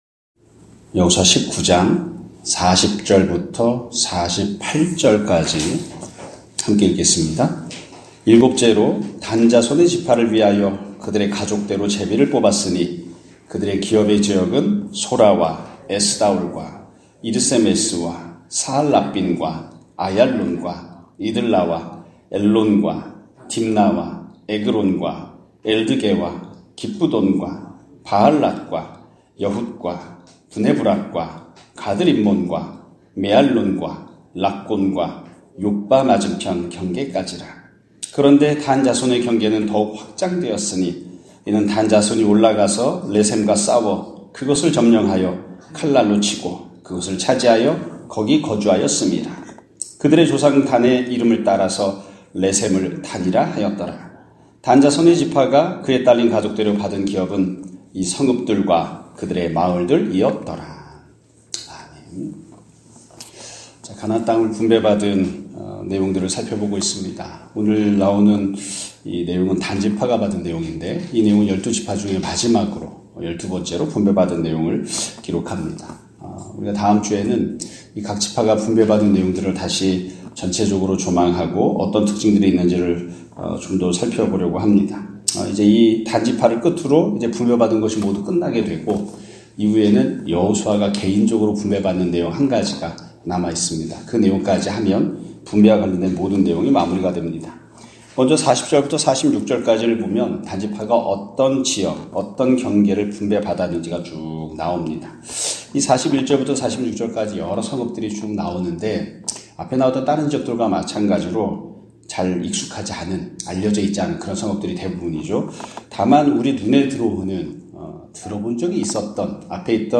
2025년 1월 10일(금요일) <아침예배> 설교입니다.